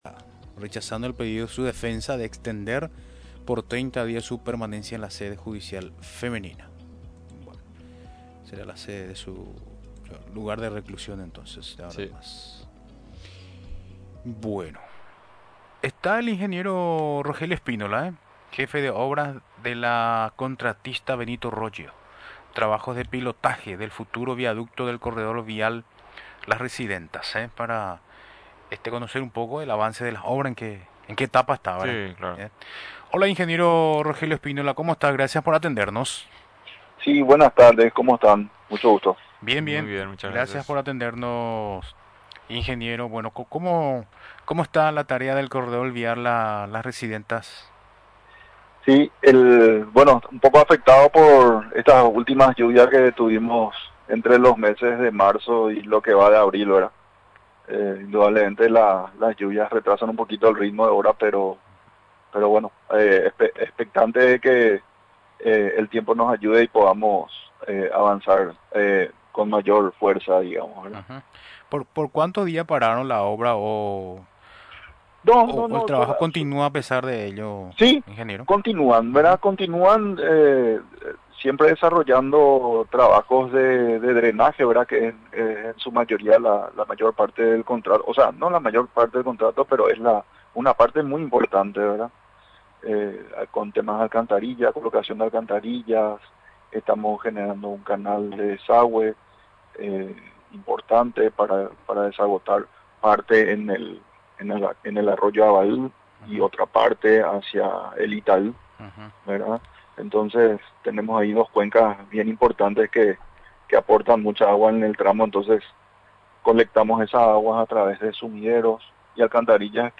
Finalmente, durante la entrevista en Radio Nacional del Paraguay, explicó los detalles técnicos de las tareas.